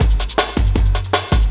06 LOOP09 -L.wav